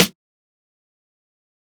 Sn (GrownWoman).wav